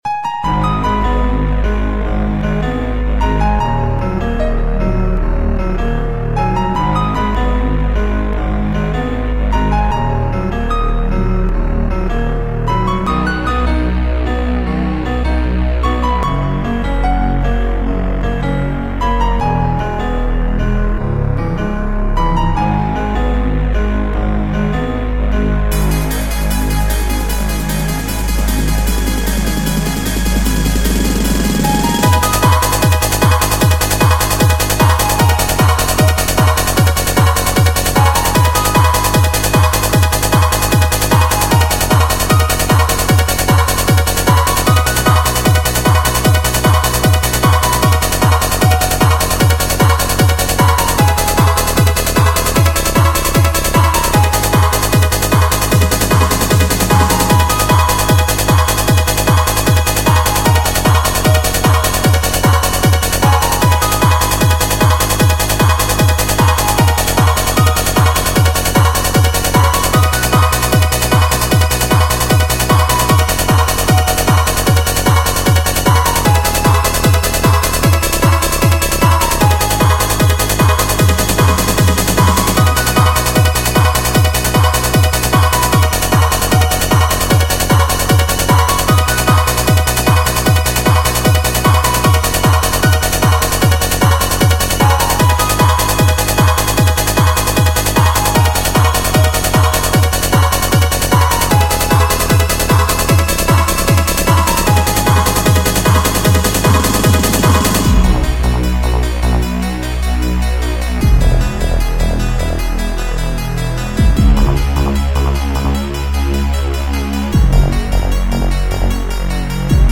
Trance Remix)file